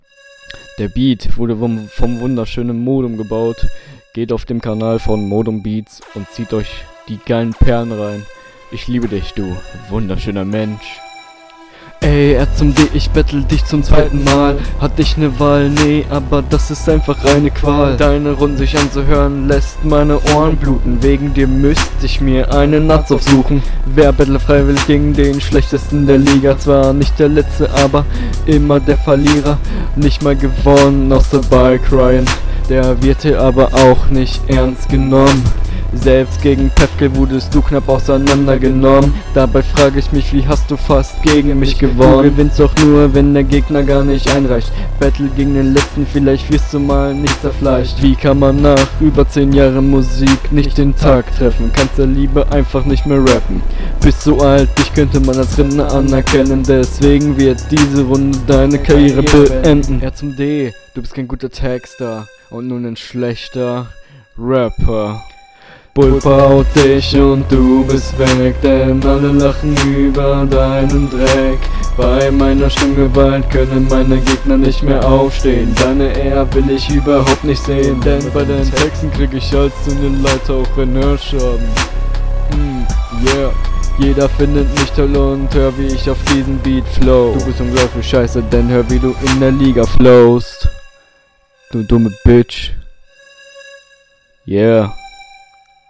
Qualitativ fällt mir jetzt in dieser Runde erst auf, dass besonders die gedoppelten Endreime übersteuern.